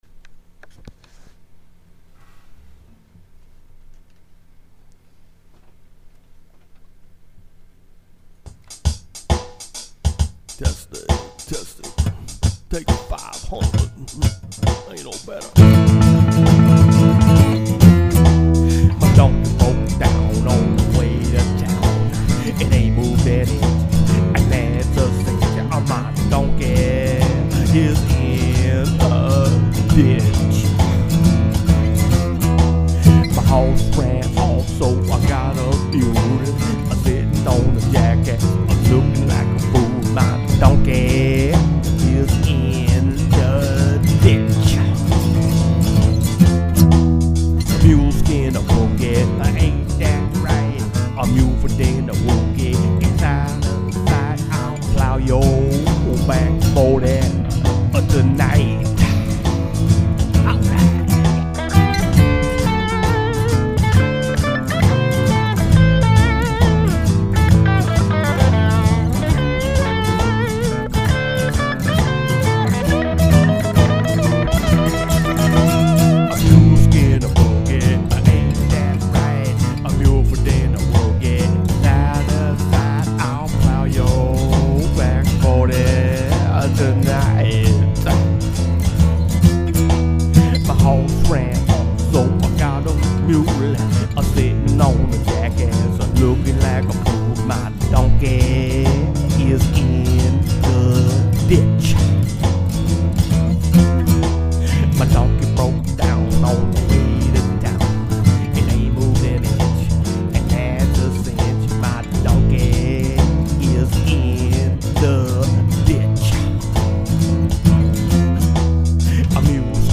Tascam DP008